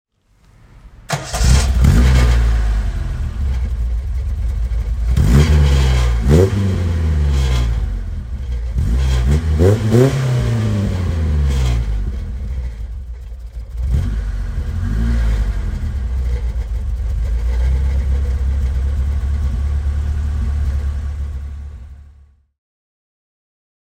Triumph Dolomite Sprint (1976) - Starten und Leerlauf
Triumph_Dolomite_Sprint.mp3